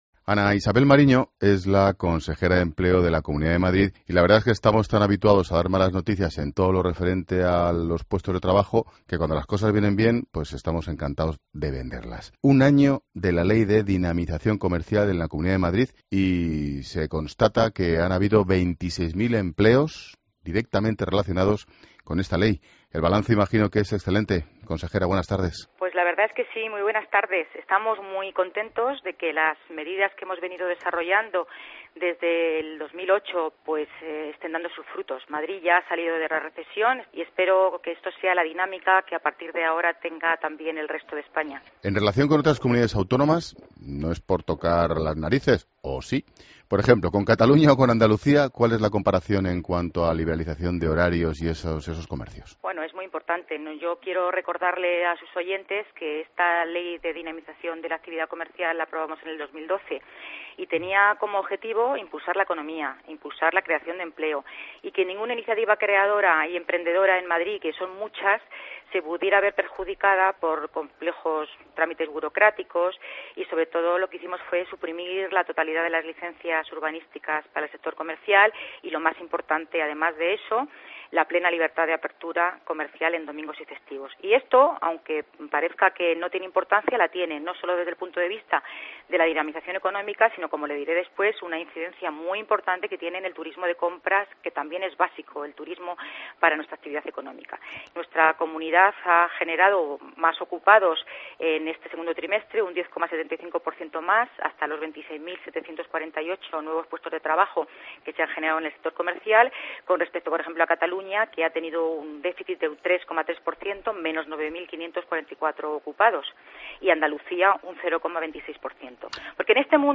Escucha la entrevista a Ana Isabel Mariño, consejera de Empleo de la Comunidad de Madrid